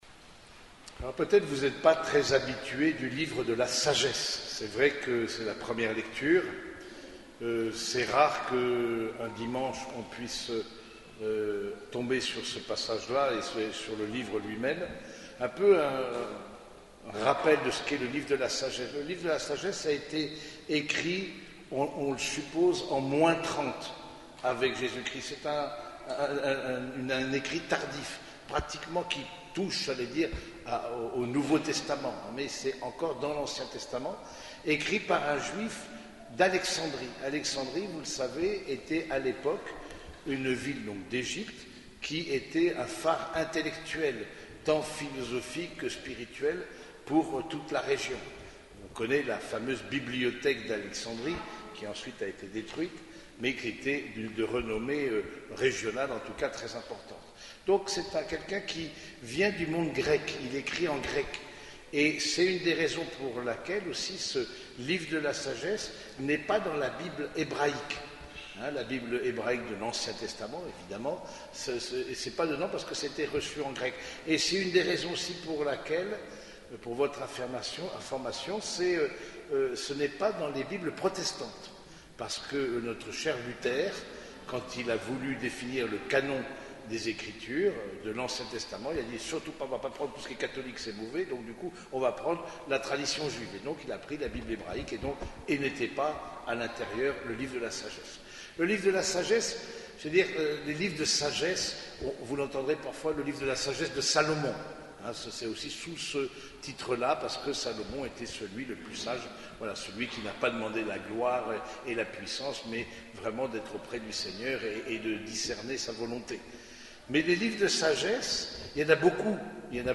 Homélie du 23e dimanche du Temps Ordinaire
Cette homélie a été prononcée au cours de la messe dominicale célébrée à l’église Saint-Germain de Compiègne.